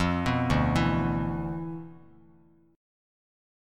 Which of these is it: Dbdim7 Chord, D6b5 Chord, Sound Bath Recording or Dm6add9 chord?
Dm6add9 chord